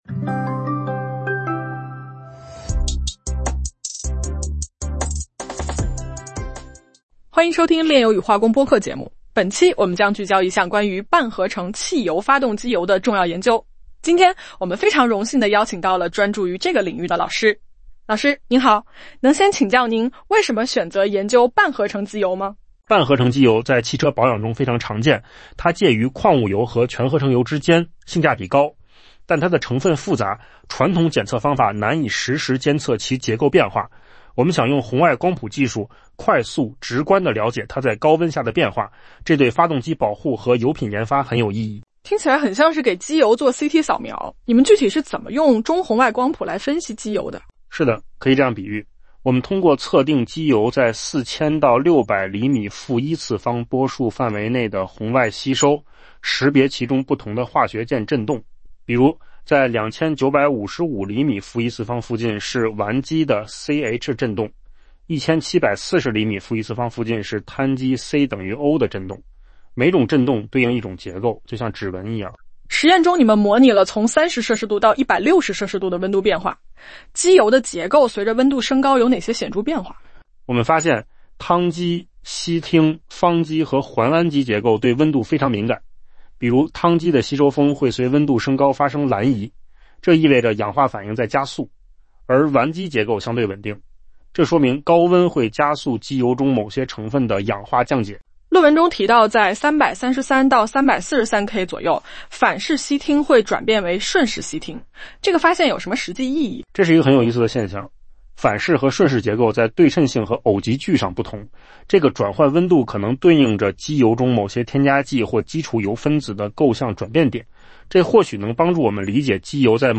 01 AI播客音频
🎧🎧🎧AI播客音频：半合成汽油发动机油结构及热变性中红外光谱研究